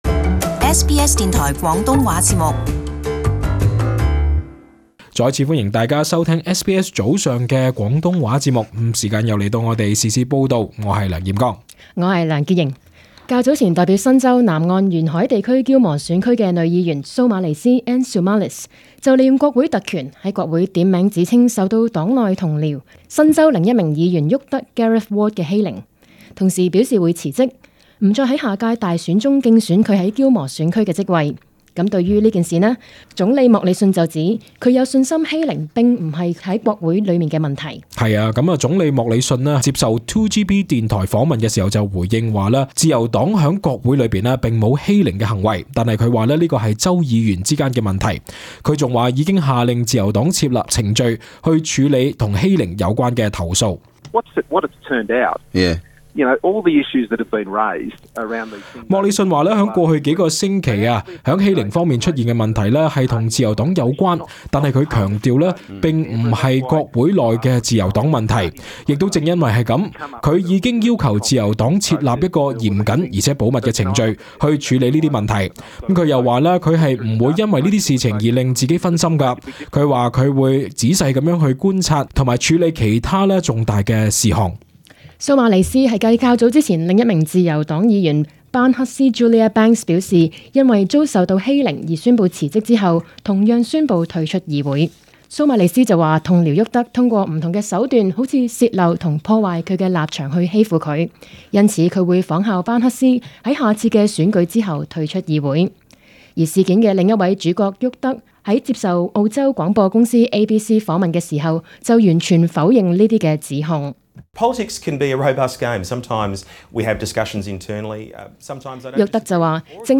【時事報導】莫里遜回應女議員遭欺凌辭職問題